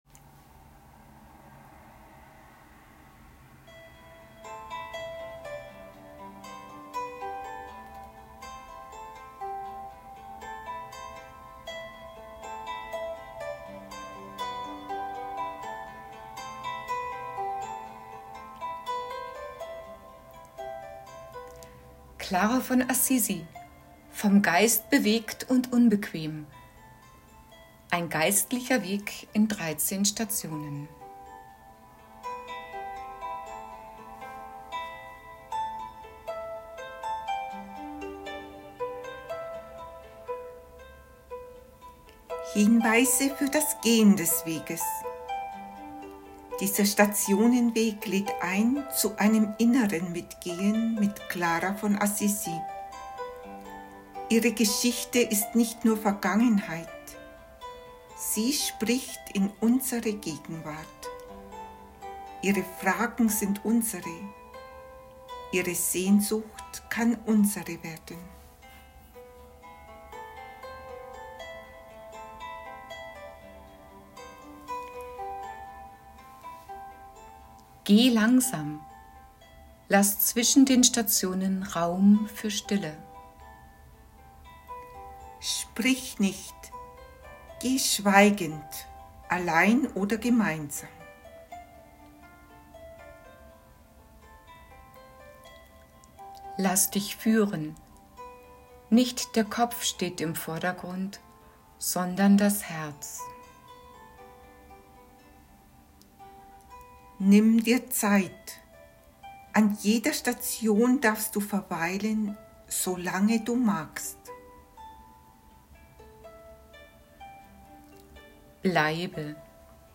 Clara-von-Assisi-von-Gott-bewegt-und-unbquem-ein-Meditationsweg-in-13-Stationen.m4a